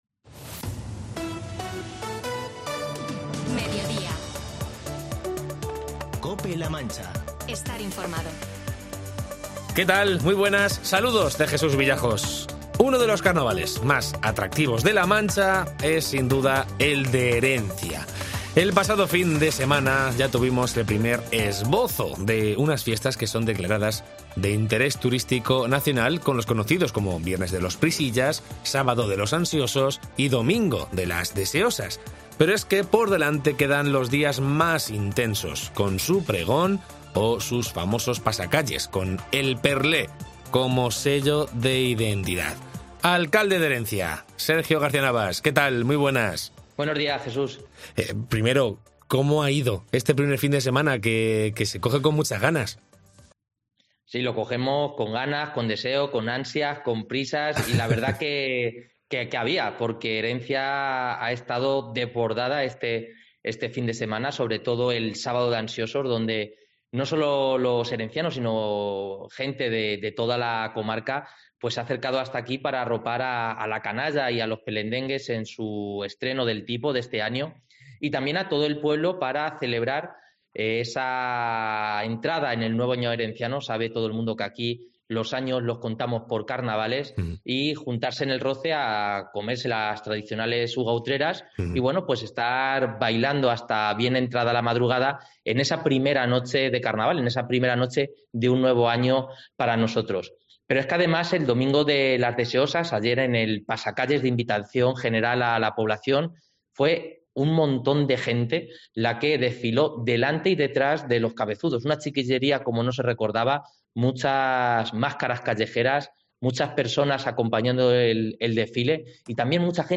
Entrevista con el alcalde de Herencia, Sergio Garcia-Navas, sobre el Carnaval de Herencia, declarado de Interés Turístico Nacional